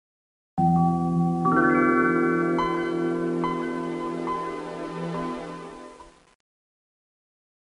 Windows 95 startup sound!